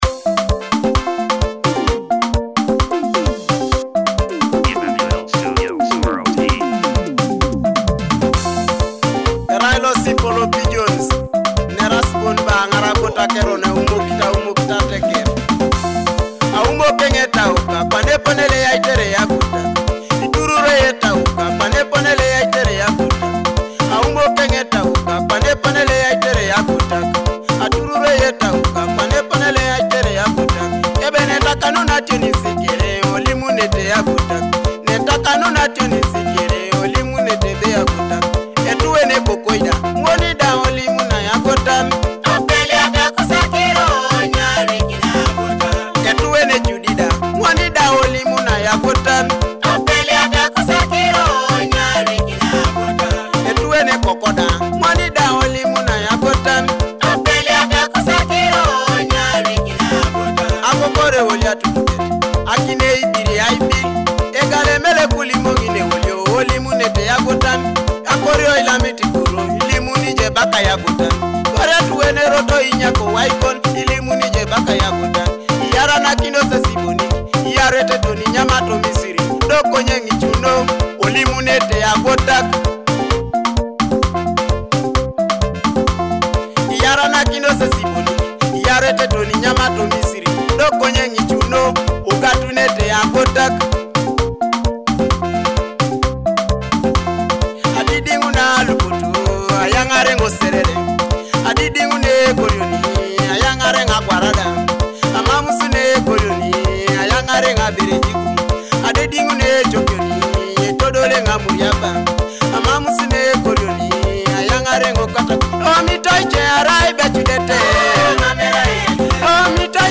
traditional songs